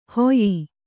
♪ hoyyi